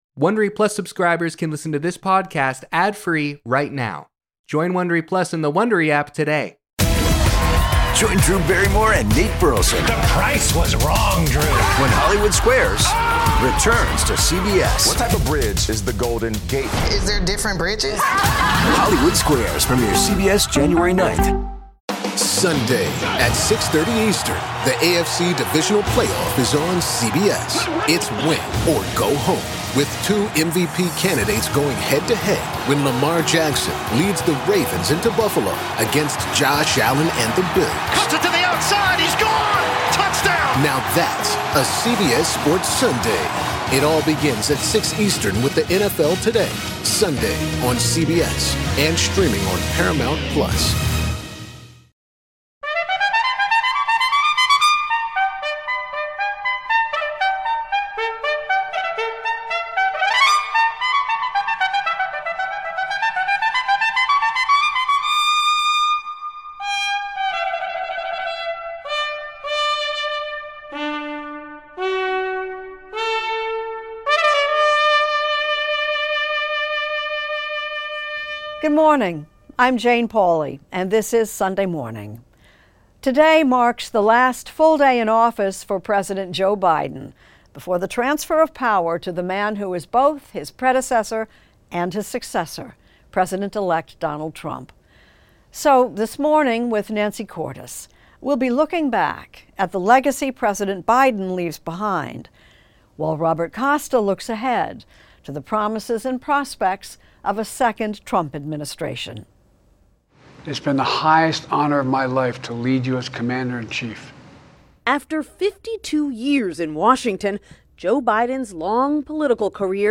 Hosted by Jane Pauley. Nancy Cordes looks at President Joe Biden's legacy as he leaves office, while Robert Costa previews the return to power of Donald Trump.